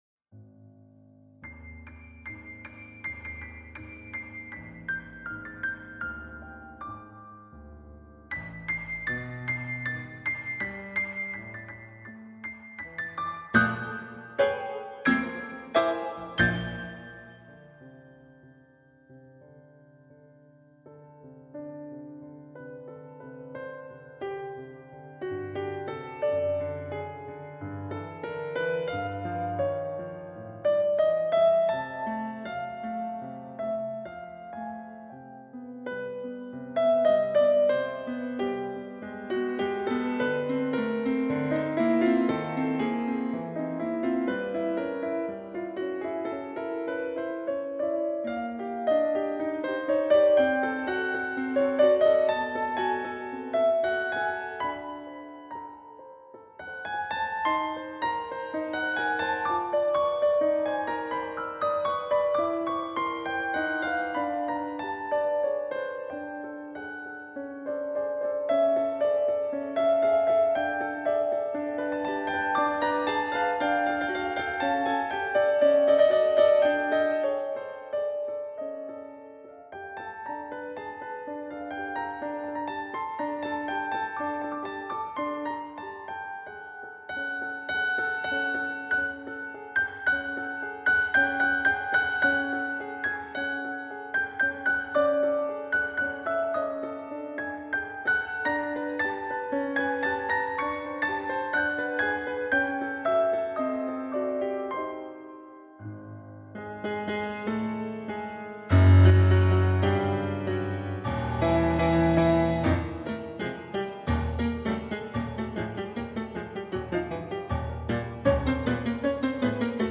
Einhundertfünfundzwanzig extrem kurze Sonaten für ein digitales Klavier
Sie sind komprimierte Miniaturen.